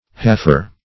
Search Result for " halfer" : The Collaborative International Dictionary of English v.0.48: Halfer \Half"er\ (-[~e]r), n. 1.